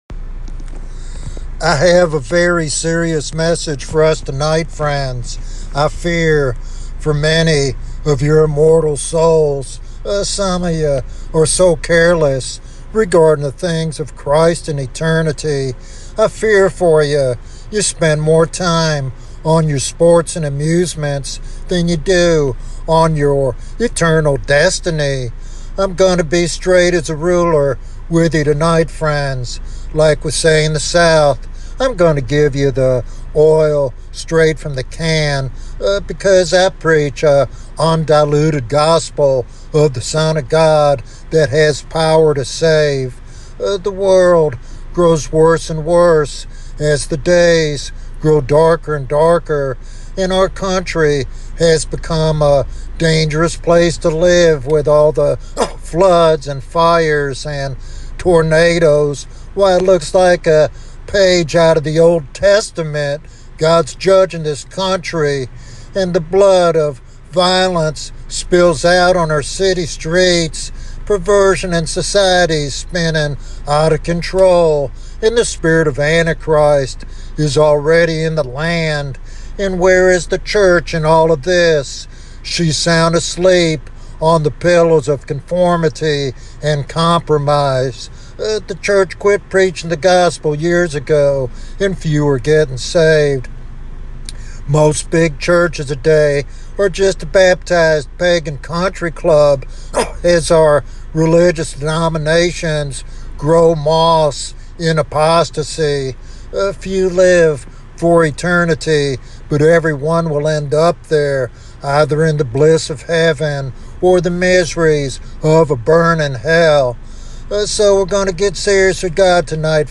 This sermon is a powerful reminder of the reality of judgment and the hope found only in genuine salvation.